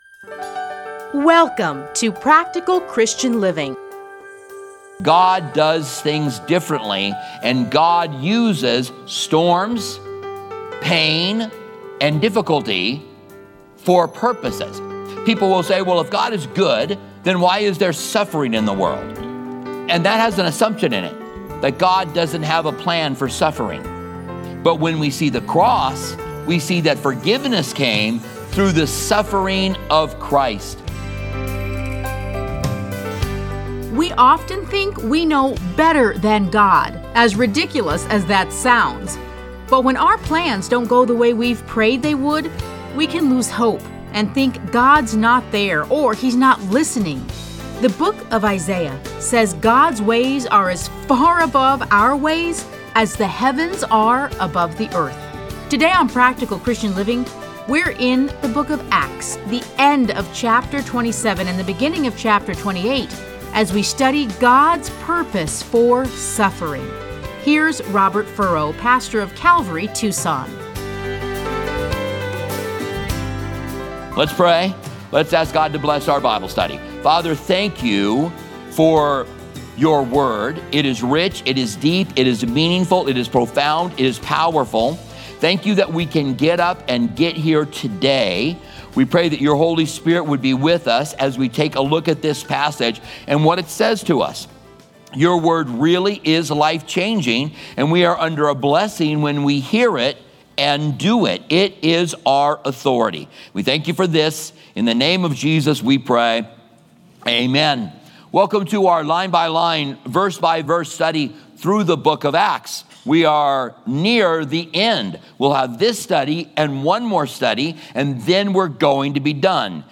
Listen to a teaching from Acts 27:27-28.